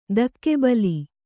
♪ dakke bali